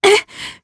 Demia-Vox_Damage_jp_02_b.wav